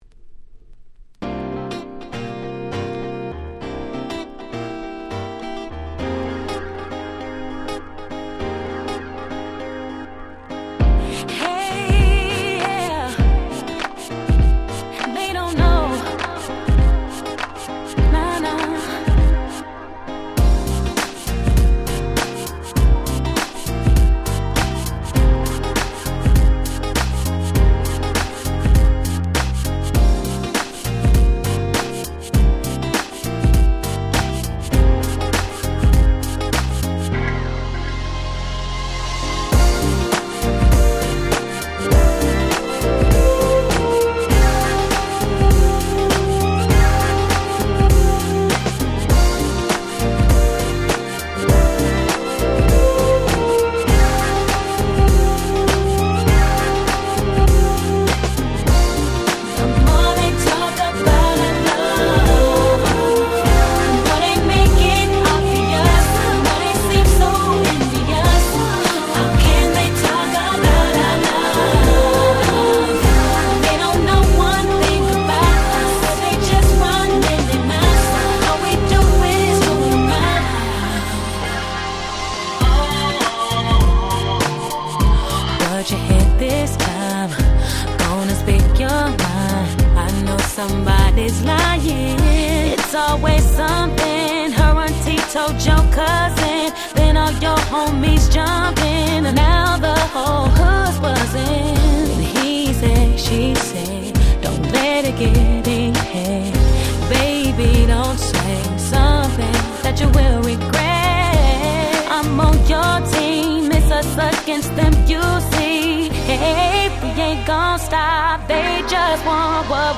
04' Smash Hit R&B !!